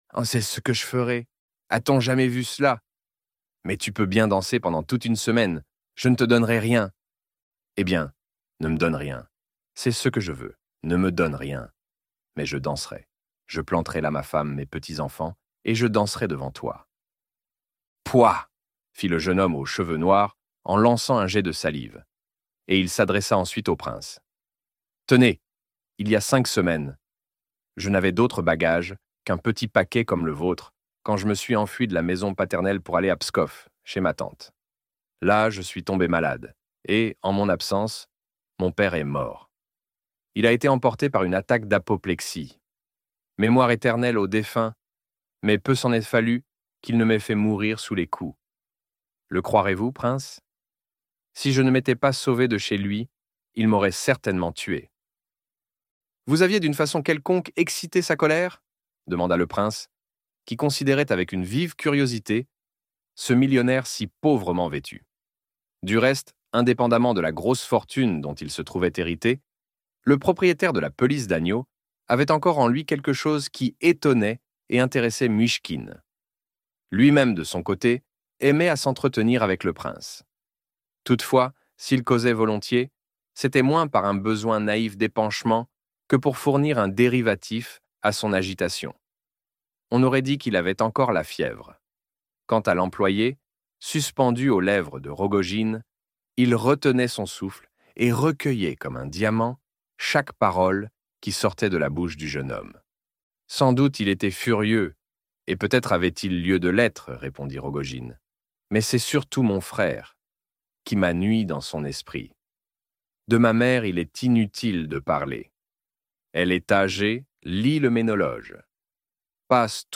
L'Idiot - Livre Audio